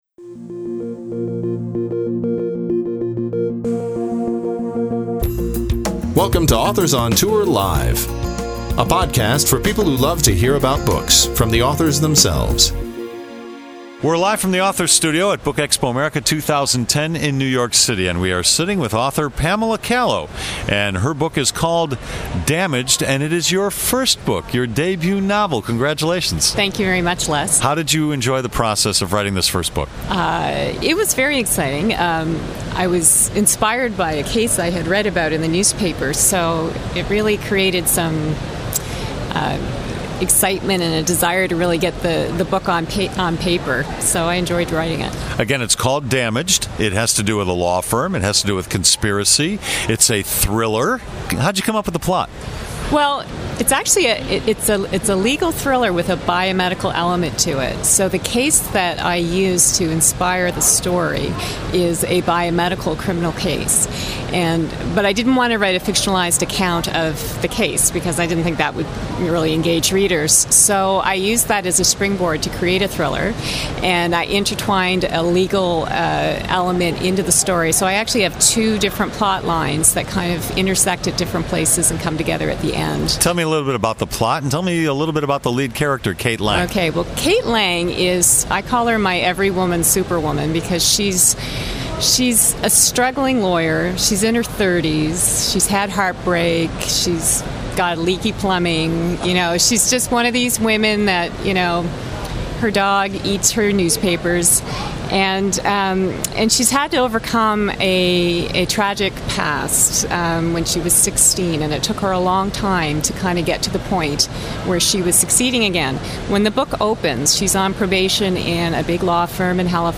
Download This podcast was recorded at BookExpo America 2010 in New York City.